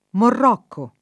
Marocco [mar0kko] top. m. — nell’uso ant., anche Marrocco [marr0kko] e Morrocco [